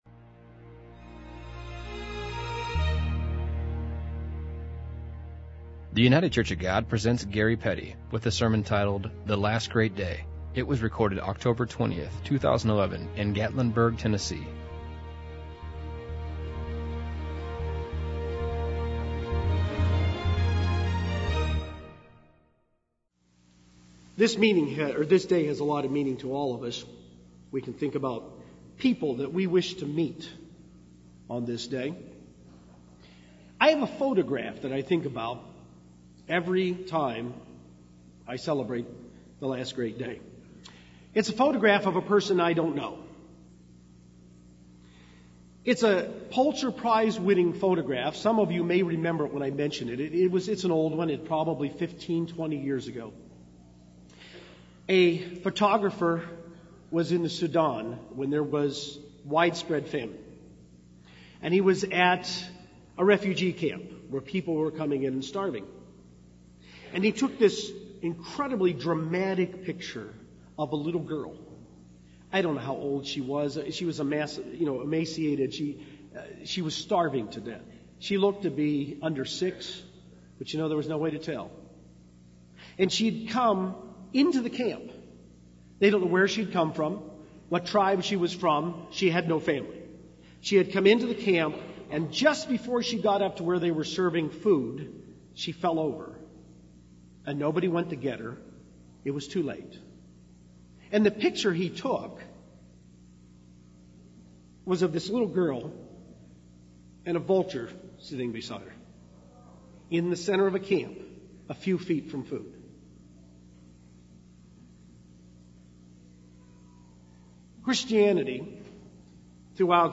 2011 Feast of Tabernacles sermon from Gatlinburg, Tennessee.
This sermon was given at the Gatlinburg, Tennessee 2011 Feast site.